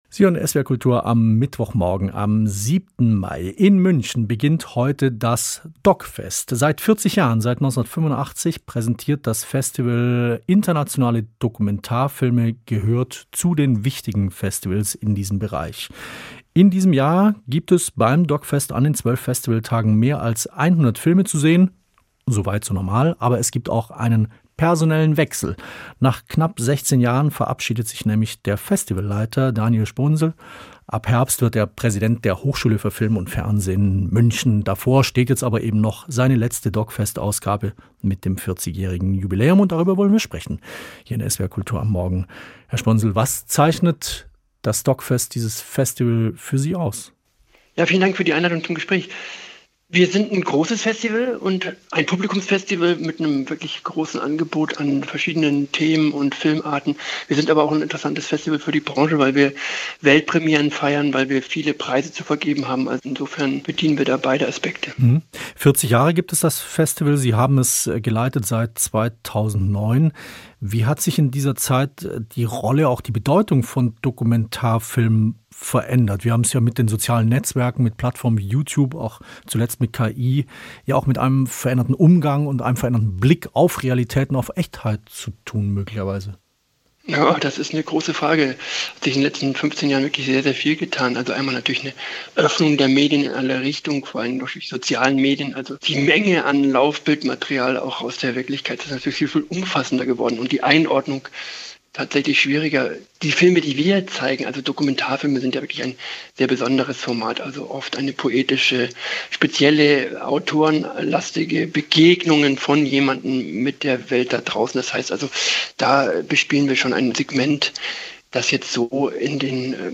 Gespräch
Interview mit